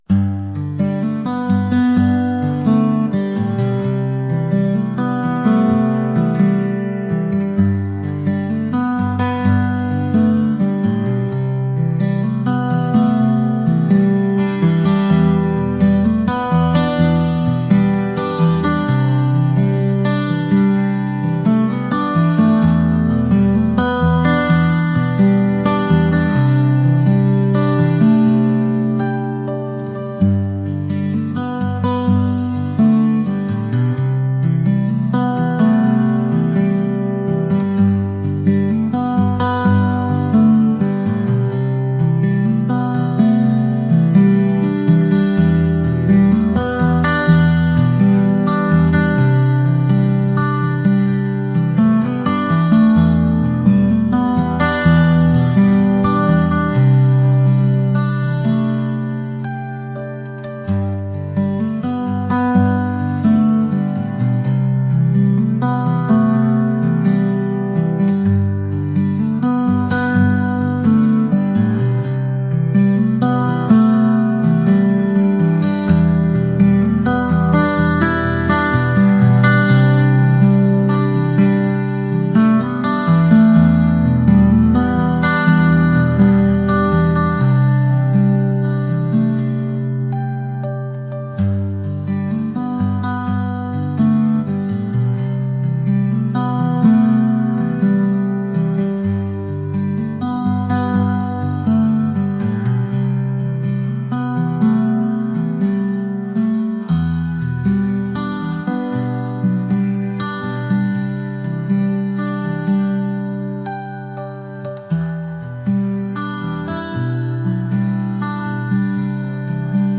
acoustic | folk